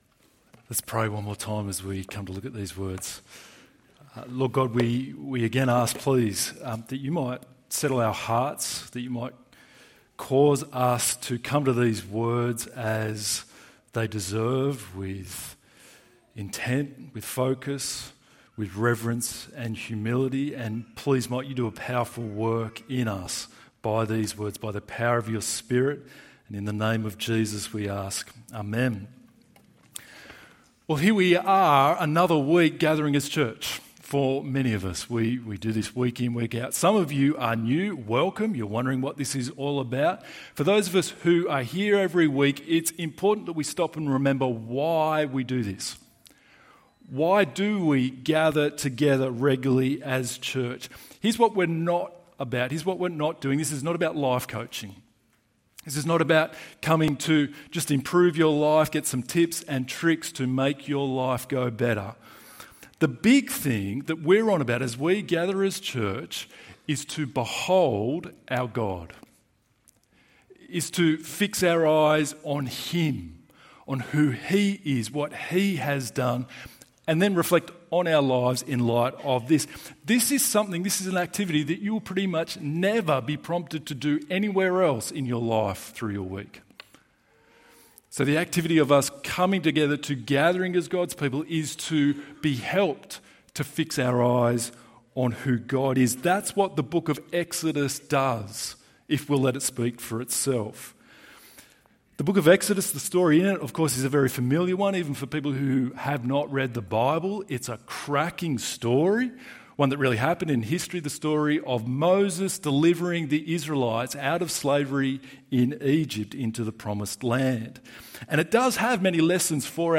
God reveals his name (I AM has sent you) ~ EV Church Sermons Podcast